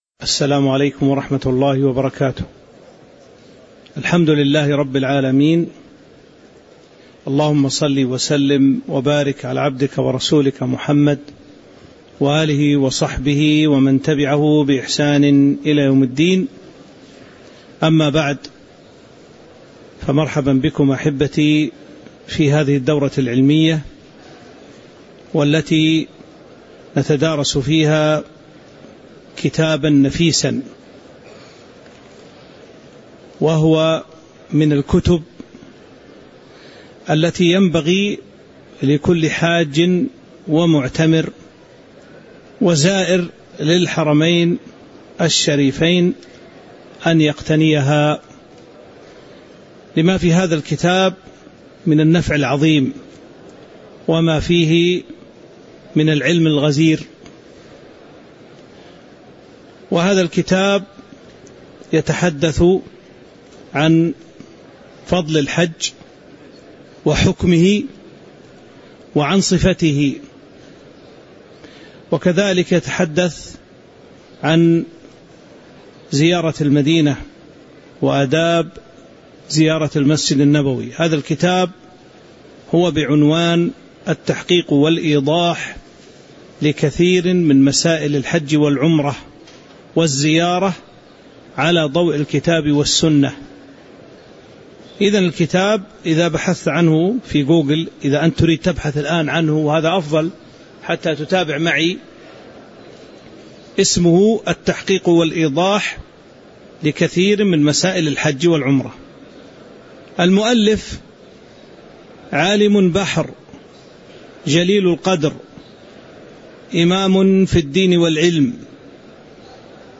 تاريخ النشر ٢٠ ذو القعدة ١٤٤٦ هـ المكان: المسجد النبوي الشيخ